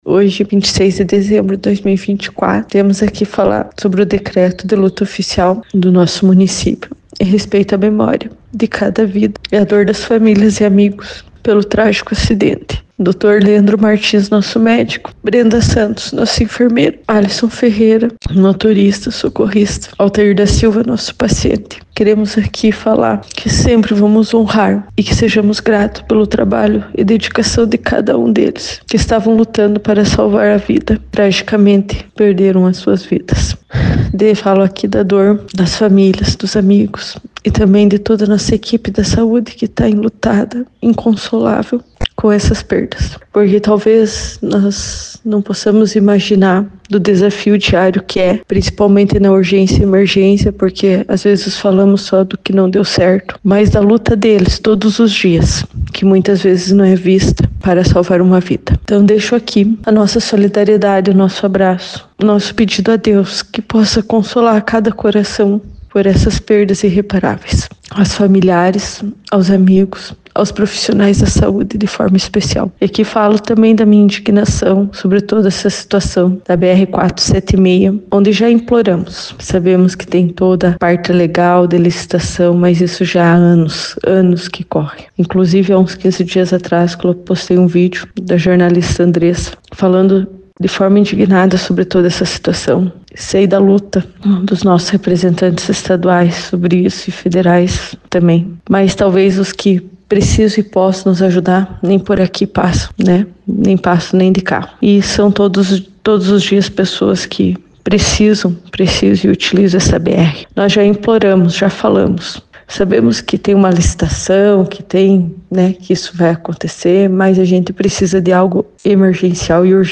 A prefeita Fernanda Sardanha, abalada com a situação, deixou sua solidariedade ás famílias das vítimas e também cobrou atenção das autoridades quanto a melhoria da rodovia.